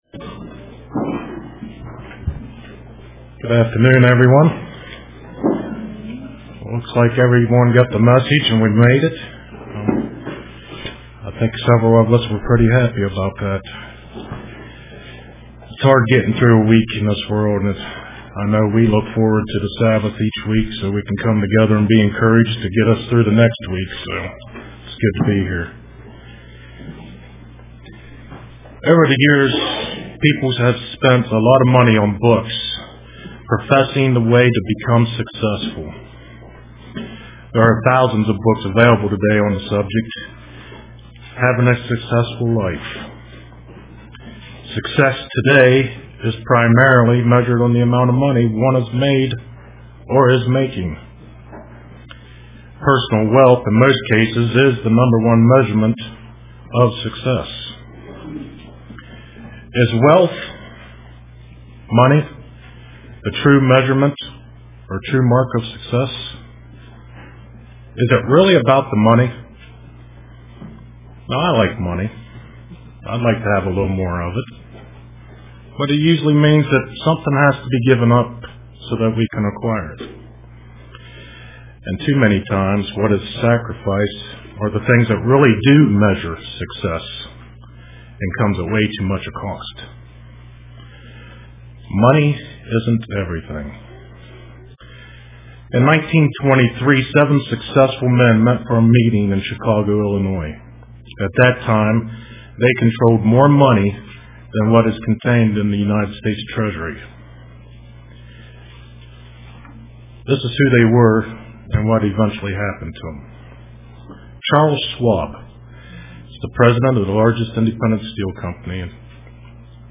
Print The Bible is all we Need UCG Sermon Studying the bible?